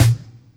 Snr Deep.wav